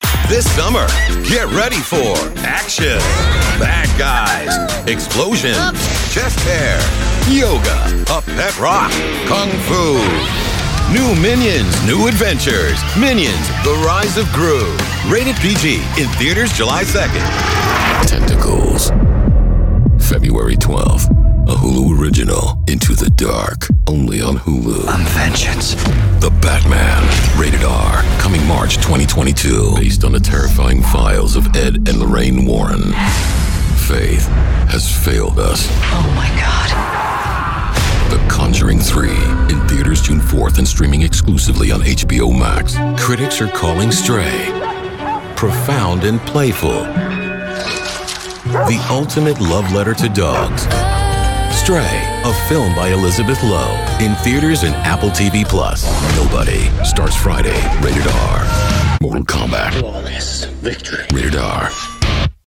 Authoritative, Razor Sharp, Witty and Conversational
Trailer Reel
Southern, NYC
Middle Aged